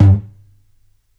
Index of /4 DRUM N BASS:JUNGLE BEATS/KIT SAMPLES/DRUM N BASS KIT 1
FLOOR TOM SHORT.wav